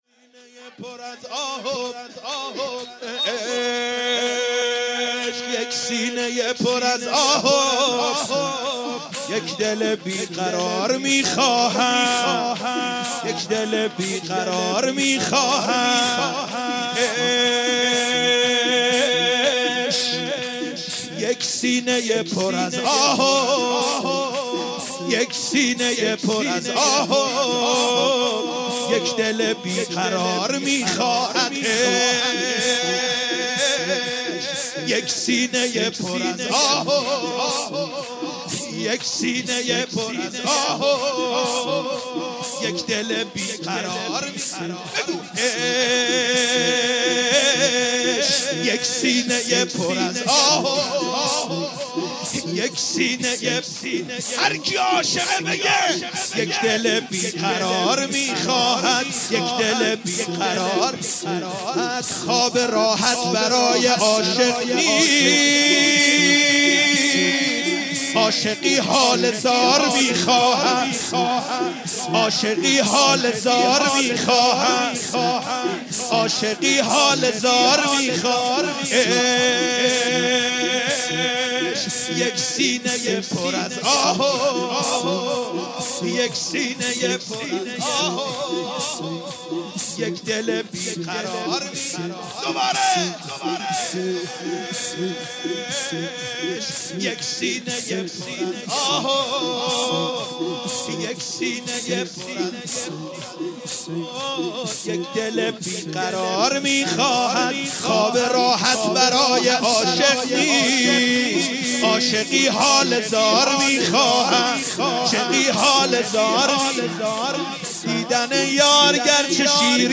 شهادت امام جواد علیه السلام
شور مداحی